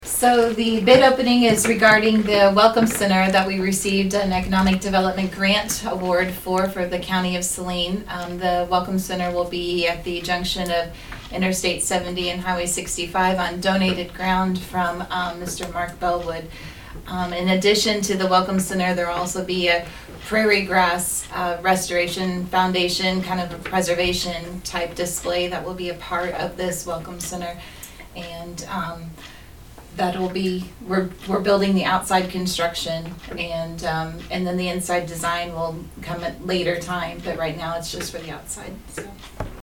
Commissioner Stephanie Gooden reminded the public what these bids were for.